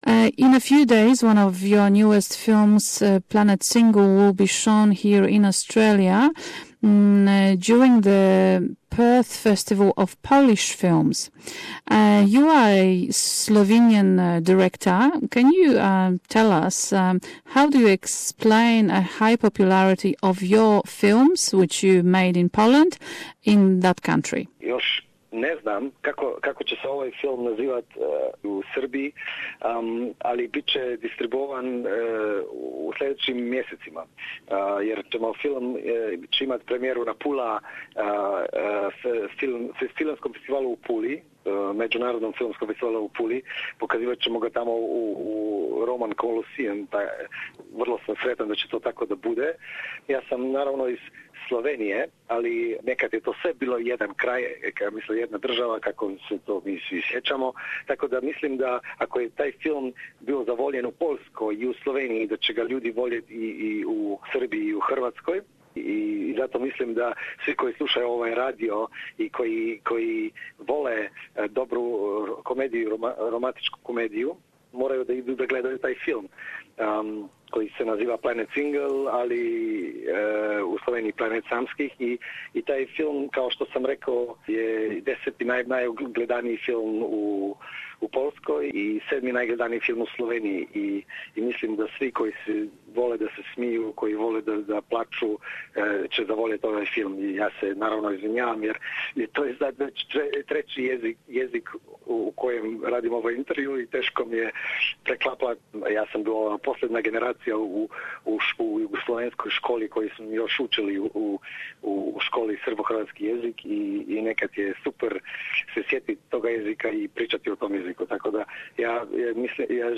Захваљујући чињеници да њен гост говори више језика, српску верзију интервјуа емитовали смо у нашем програму.